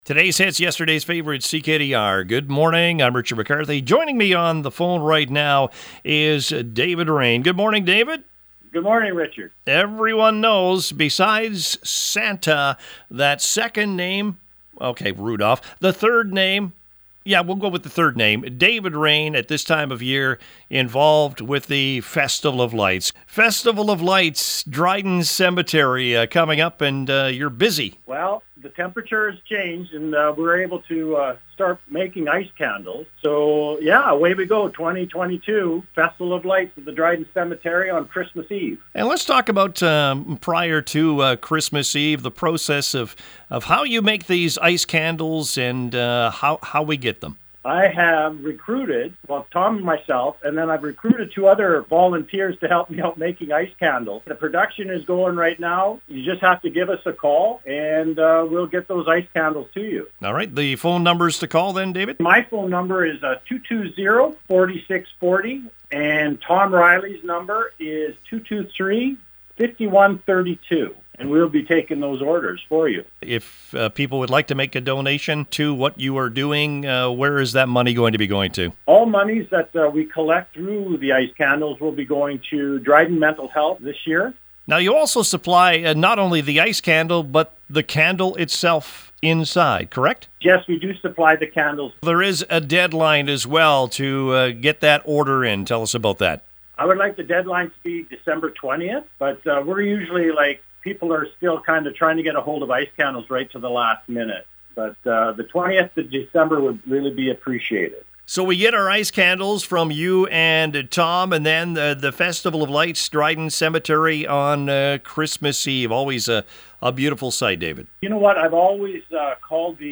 Here’s the interview: